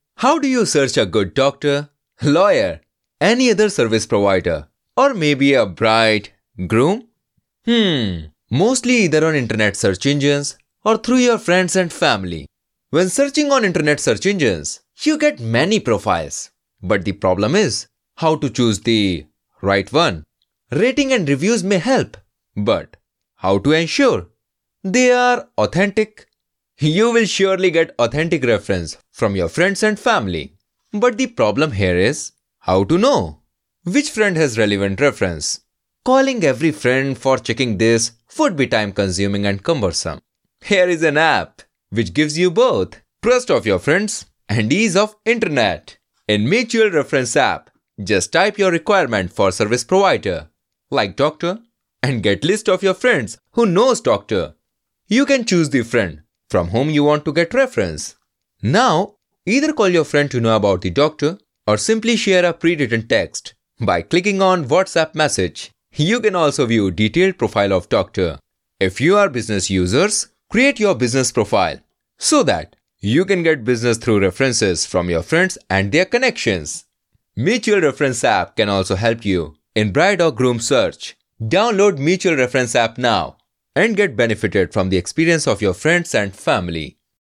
Male
Narration
App Promotion In English Voice
All our voice actors have professional broadcast quality recording studios.
0707Indian_English_voice_over_artist__deep_male_voice.mp3